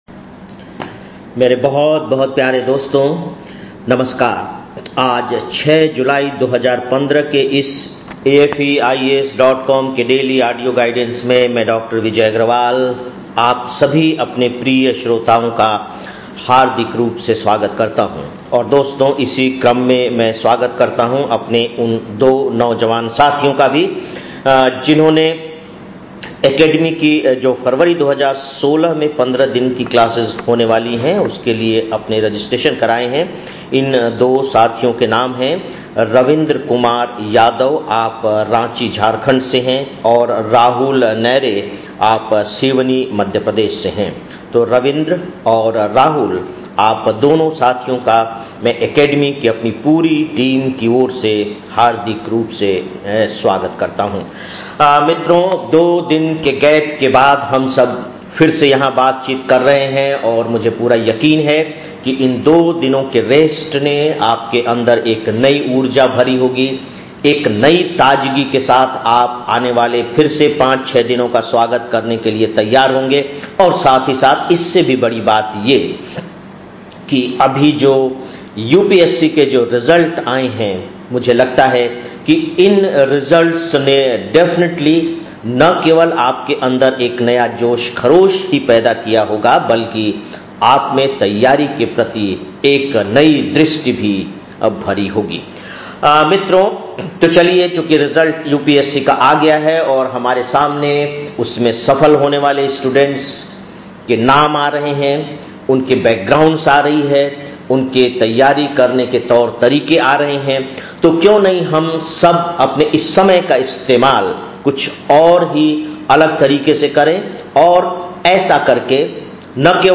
06-07-15 (Daily Audio Lecture) - AFEIAS